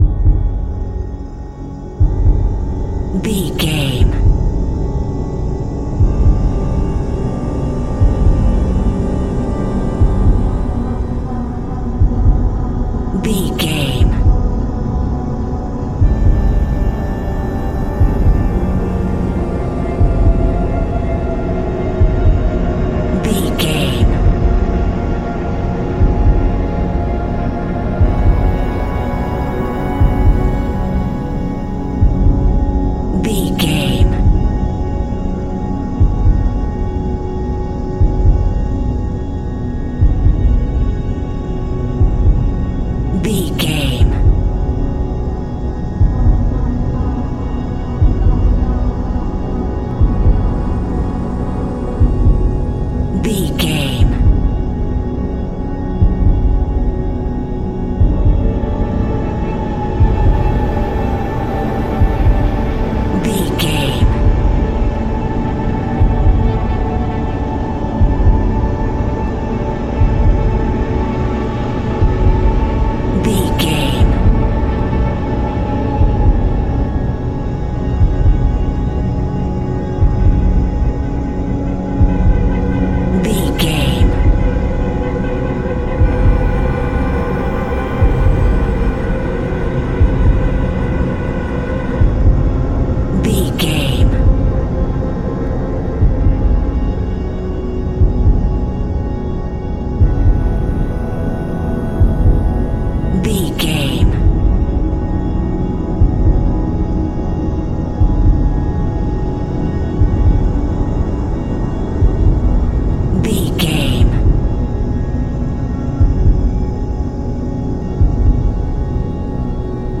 Heartbeat Thriller Music Cue.
Atonal
tension
ominous
dark
eerie
synthesizer
keyboards
pads
eletronic